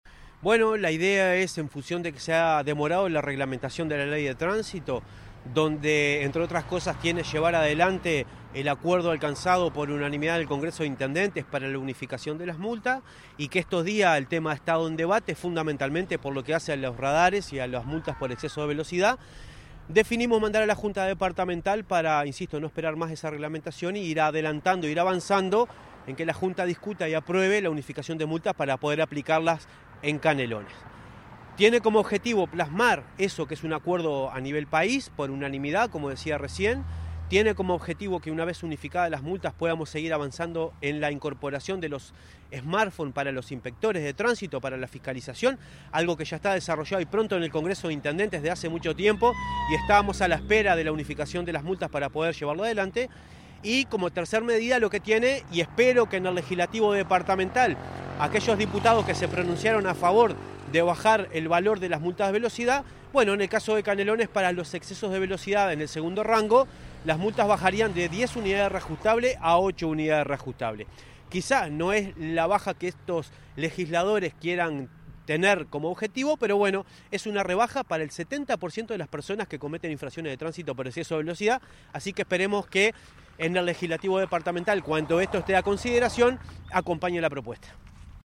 Entrevista a Marcelo Metediera, director de Transporte y Tránsito de la Intendencia de Canelones: